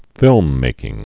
(fĭlmmākĭng)